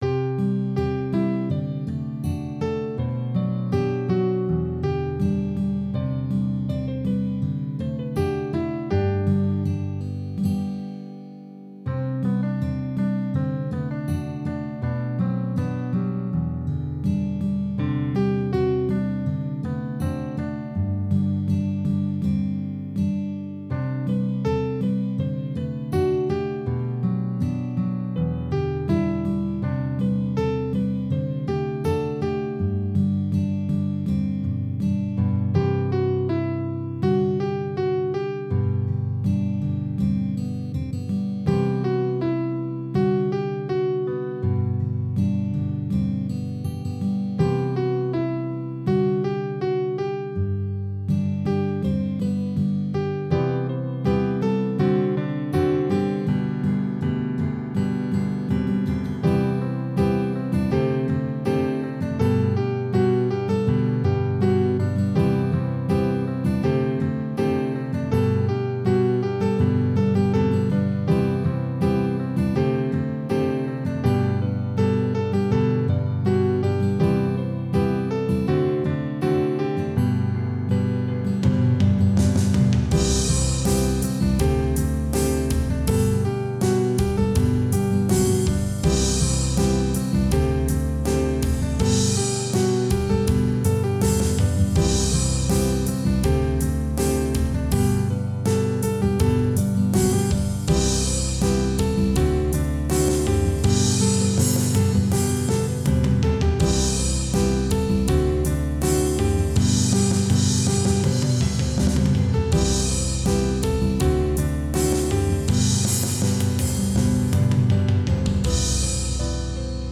曲名: Life. 純音樂演奏, 編制是鼓, 木吉他, 鋼琴, bass.